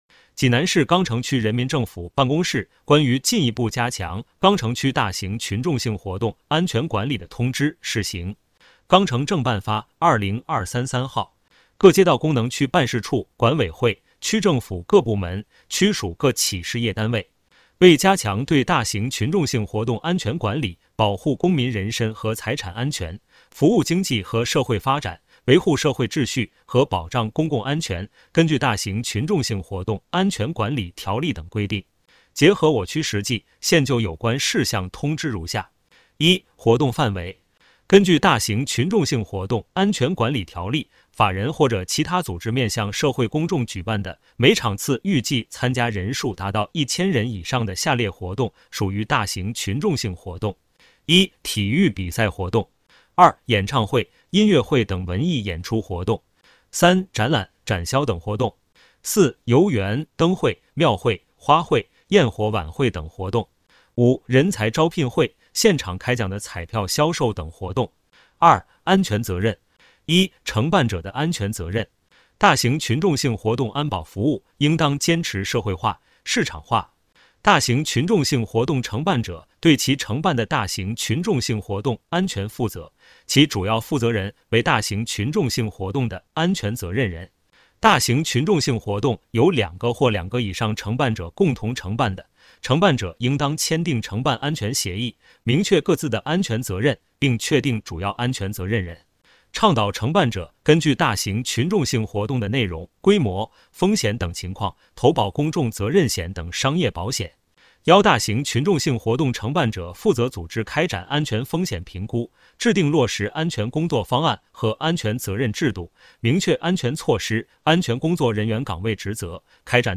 有声朗读：济南市钢城区人民政府办公室关于进一步加强钢城区大型群众性活动